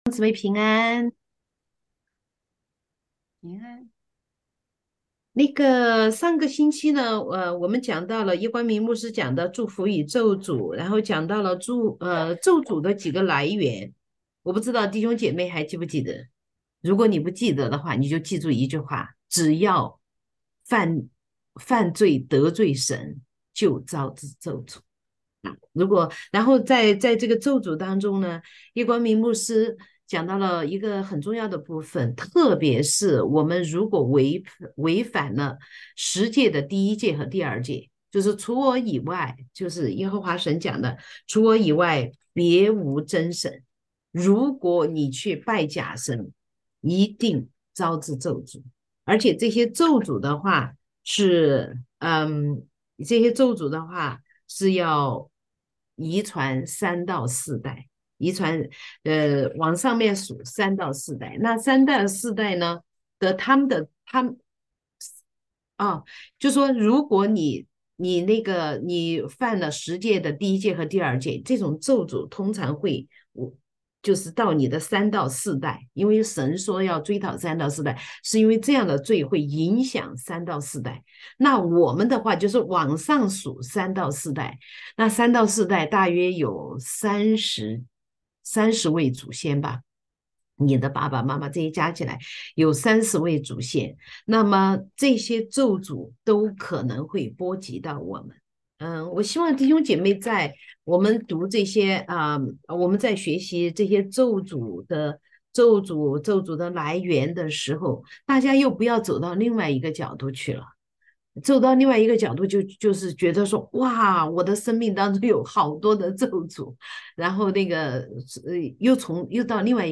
4-答疑录音-如何破除咒诅下.mp3